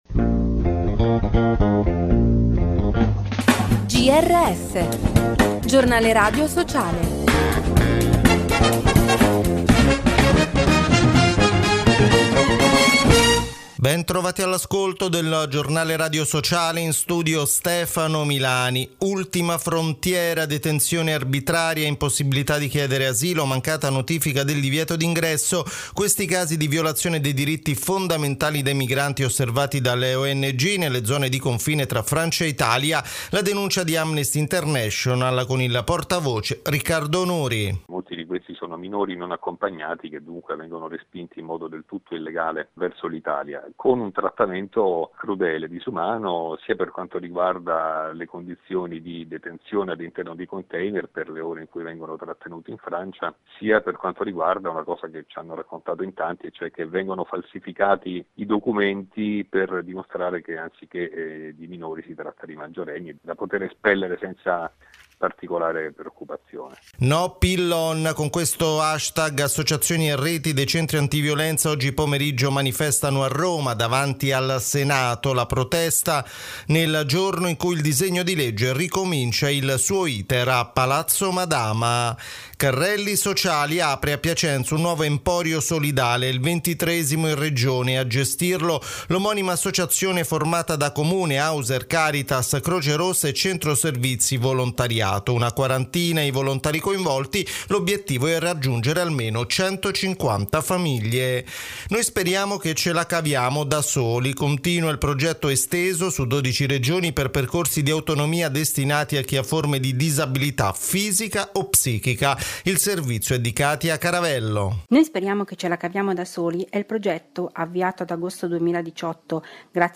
Bentrovati all’ascolto del Grs week, l’approfondimento settimanale del Giornale Radio Sociale.